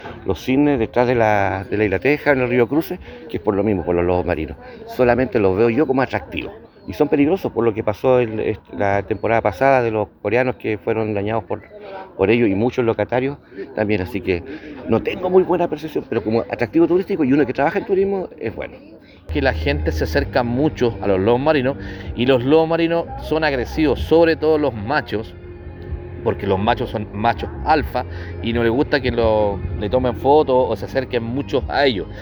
En conversación con La Radio, guías turísticos que trabajan en la costanera de Valdivia advirtieron que los lobos marinos pueden presentar conductas agresivas para las personas.